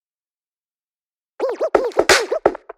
EMO HIPHOP LOOP TRACK BPM86 Em/Gmaj INTORO